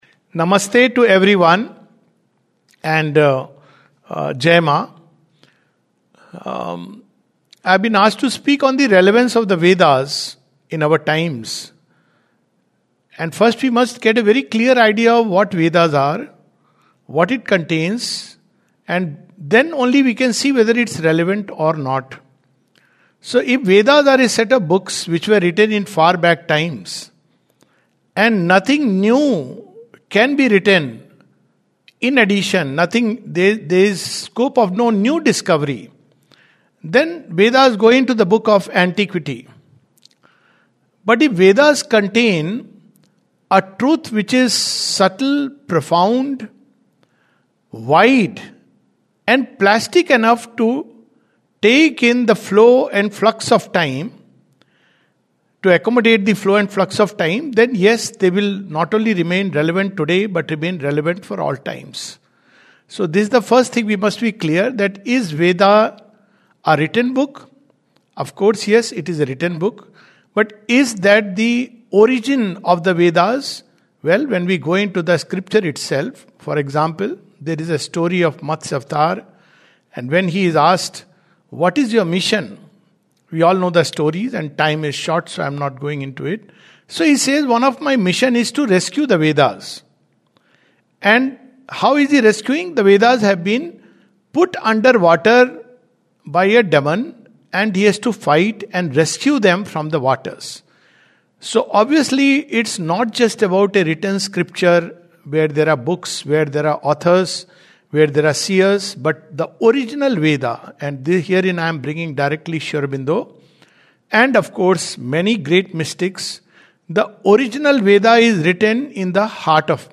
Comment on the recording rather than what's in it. recorded on February 12, 2026, in Pondicherry.